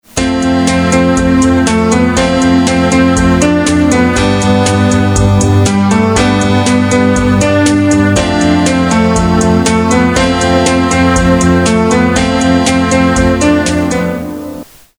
Akú skladbu predstavuje nižšieuvedené polyfonické zvonenie?